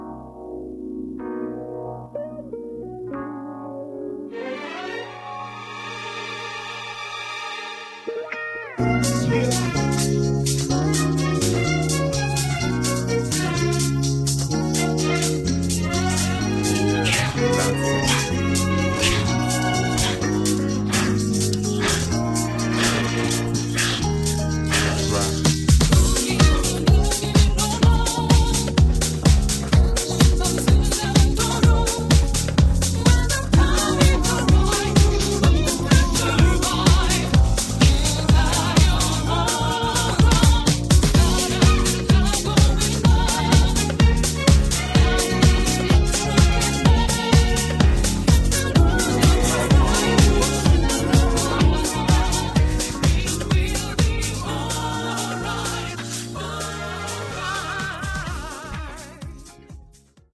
음정 -1키 4:20
장르 가요 구분 Voice MR